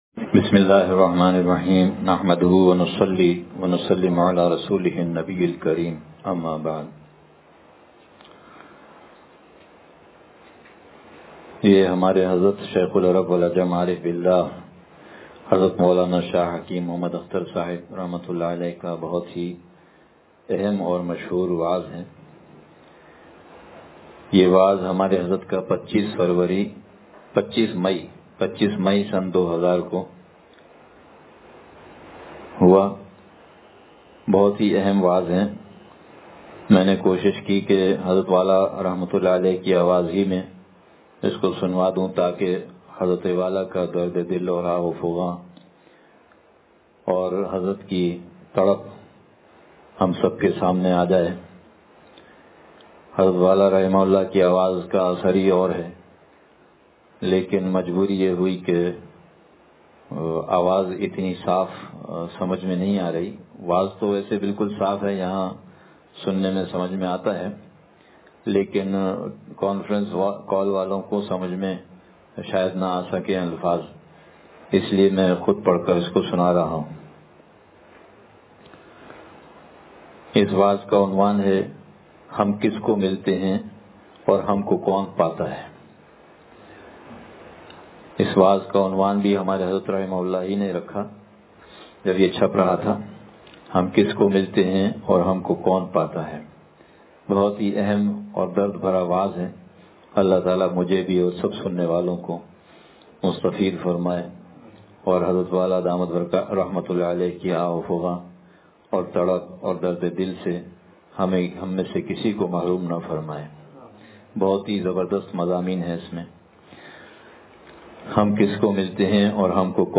حضرت والا رحمتہ اللہ علیہ کا وعظ ہم کس کو ملتے ہیں اور ہم کو کون پاتا ہے